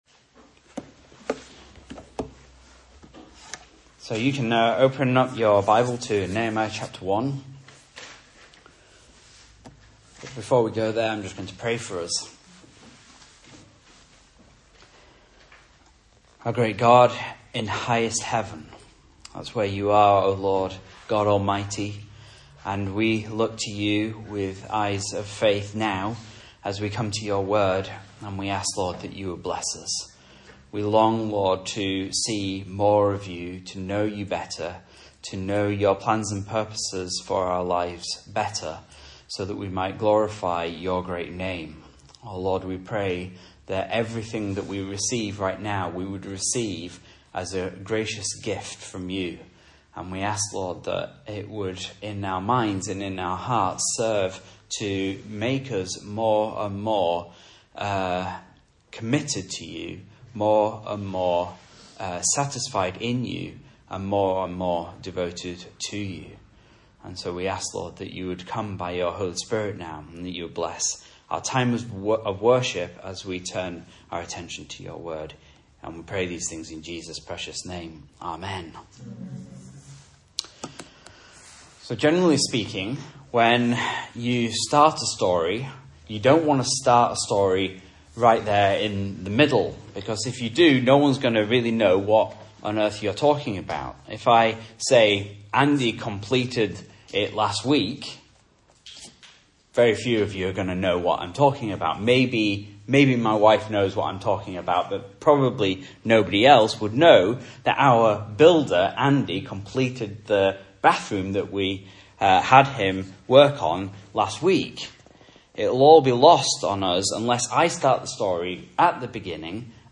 Message Scripture: Nehemiah 1 | Listen